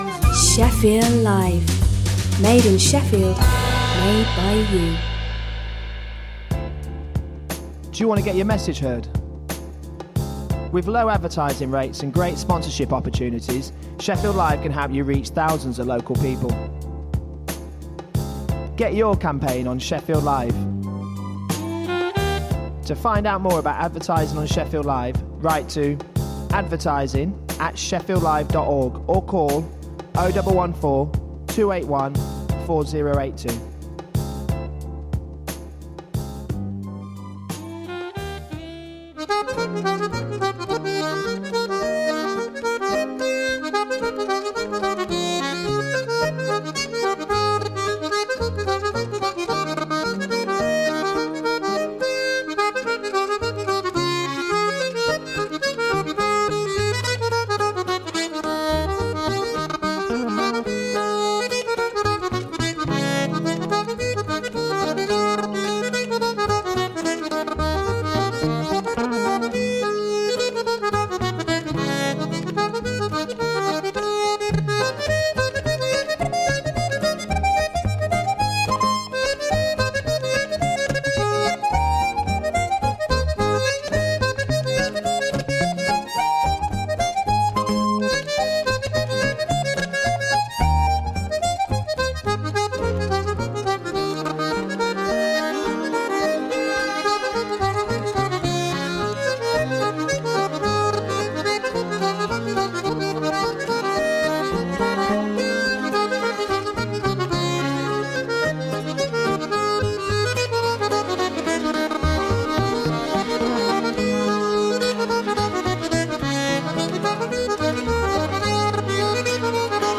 Shefffield Live presents…Aaj Ka Sabrang : A mix of different flavours of Asian music from the sub-continent and chat.